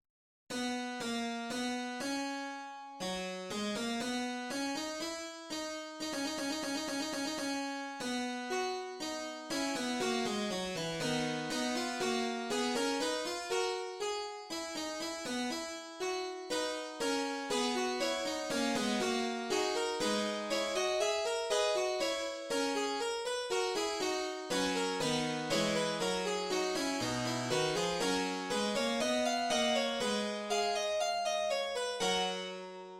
Fugue
4 voix —  , 34 mes.
⋅ fugue concertante
C'est l'une des fugues « des plus souriantes »[4].